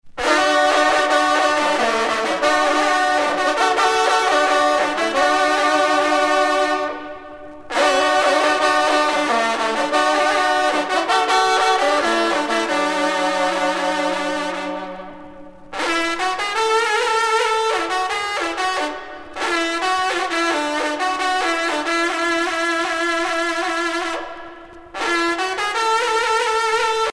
sonnerie vénerie - fanfare d'équipage
Pièce musicale éditée